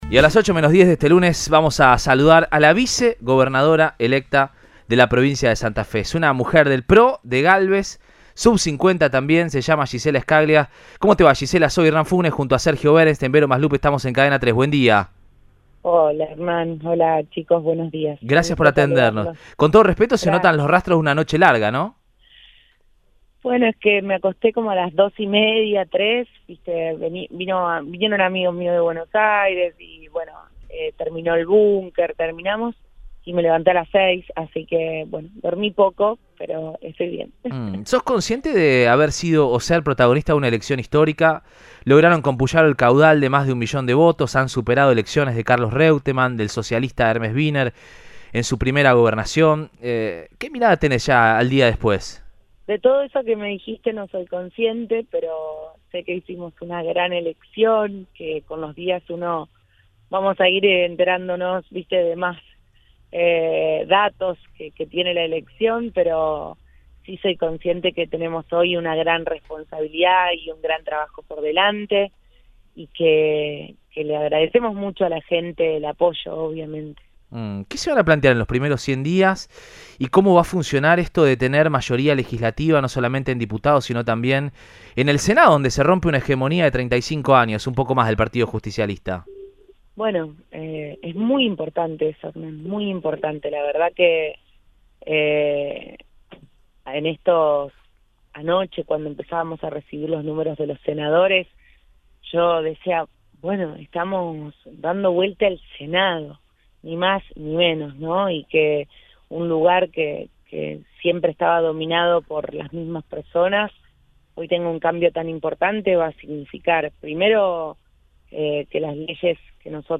“Hicimos una gran elección. Con el paso de los días sabremos más datos, pero tenemos una gran responsabilidad y un gran trabajo por delante. Agradecemos mucho el apoyo”, expresó Scaglia en Radioinforme 3, por Cadena 3 Rosario.